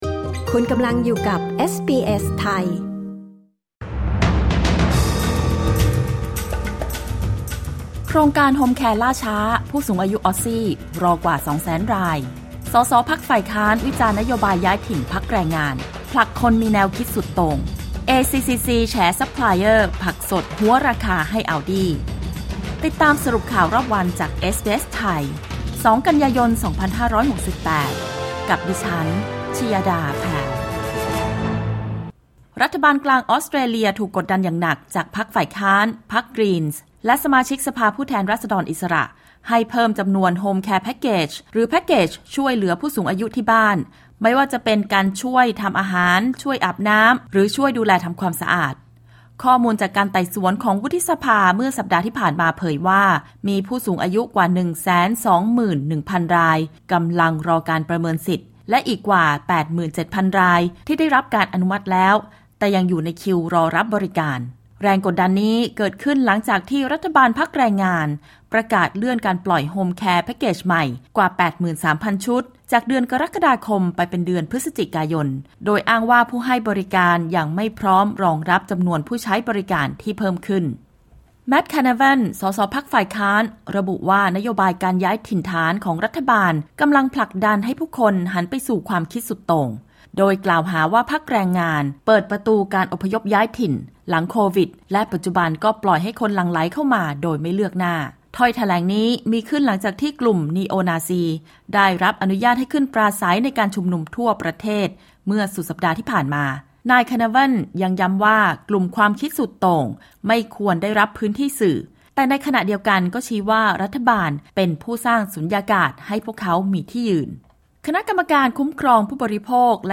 สรุปข่าวรอบวัน 2 กันยายน 2568